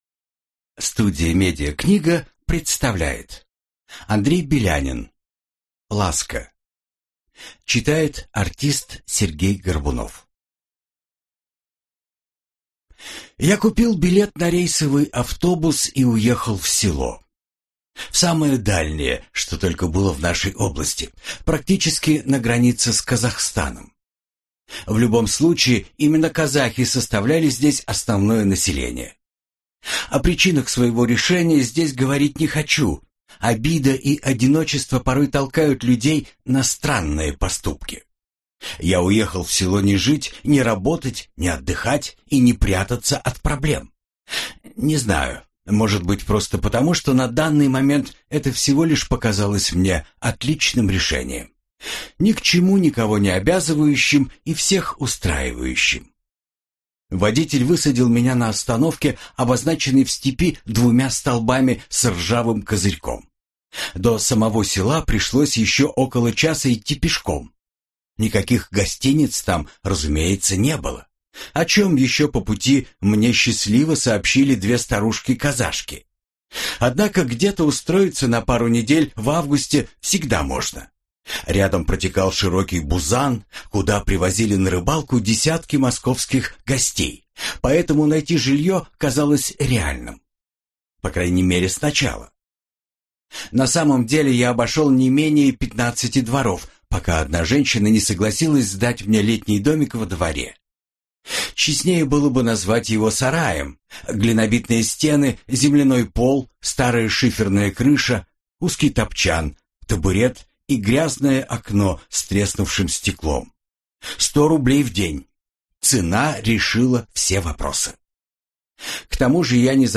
Аудиокнига Ласка | Библиотека аудиокниг